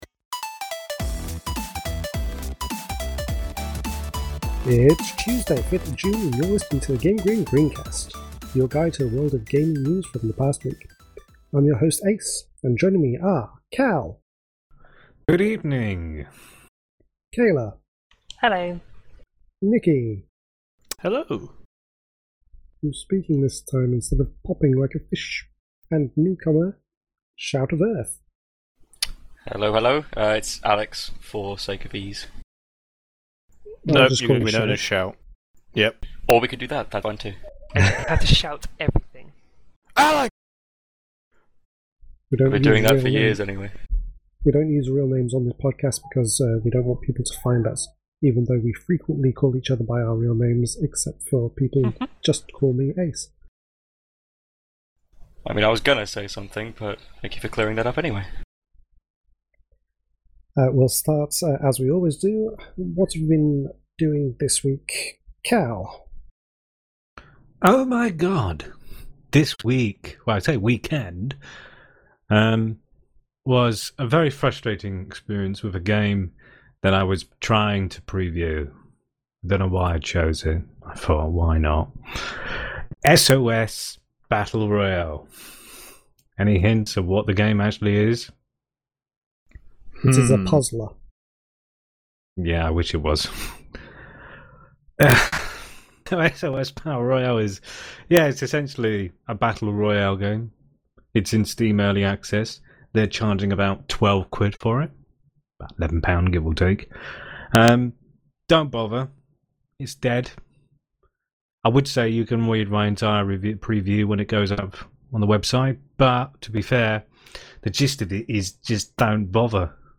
This week on the live GrinCast, we talked about Elite: Dangerous Beyond - Chapter Two release dated, Evolve Stage 2 is shutting down and Sea of Thieves The Hungering Deep free update is here.